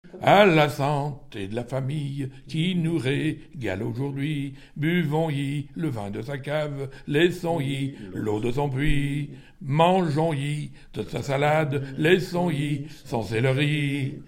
circonstance : bachique ; circonstance : fiançaille, noce ;
Genre laisse
Pièce musicale inédite